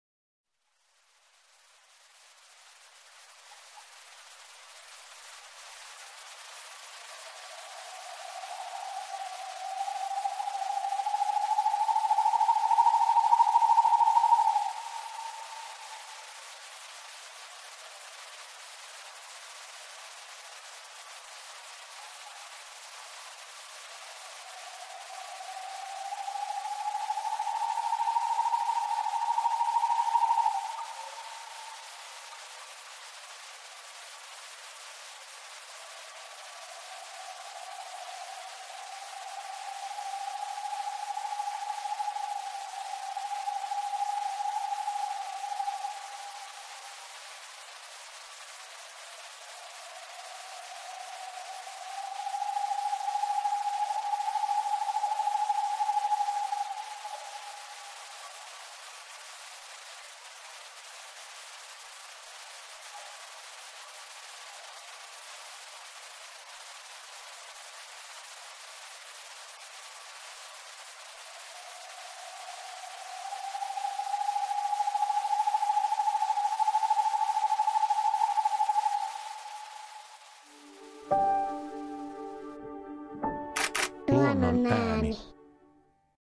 Kuuntele: Hiiripöllö
Hiiripöllön soidinääni on nopeaa pulinaa ”ululululu…”, joka voi välillä hieman nousta ja laskea.